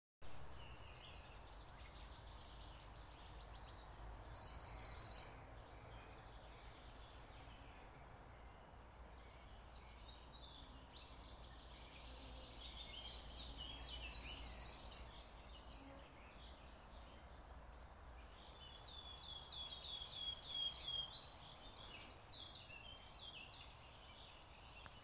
Птицы -> Славковые ->
пеночка-рещетка, Phylloscopus sibilatrix
СтатусПоёт